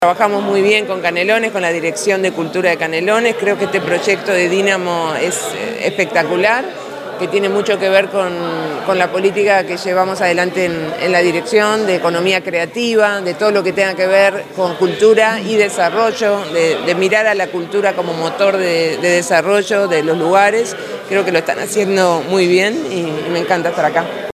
mariana_wainstein_directora_nacional_de_cultura.mp3